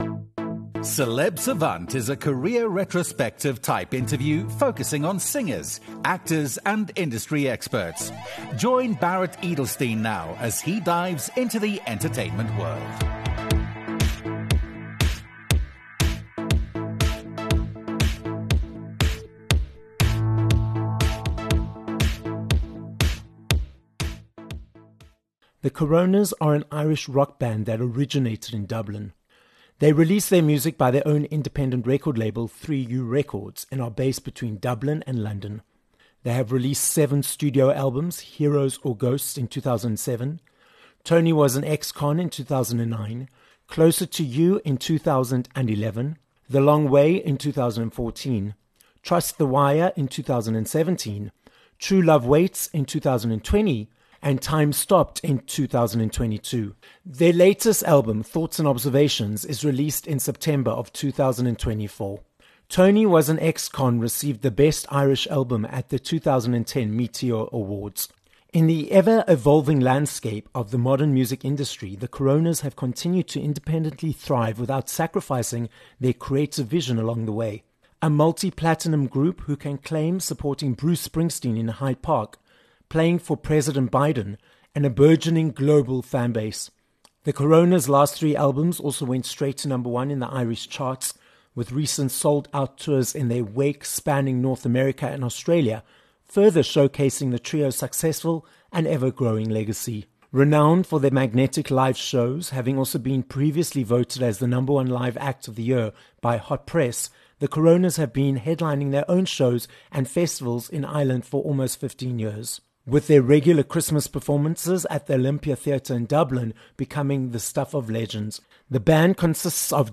Danny O'Reilly - an Irish singer, songwriter and musician from The Coronas - joins us on this episode of Celeb Savant. Danny explains how they felt when the pandemic hit with the same name as the band's, their success in the industry for over 15 years. He also chats about their upcoming tour to Australia and their latest album - Thoughts and Observations.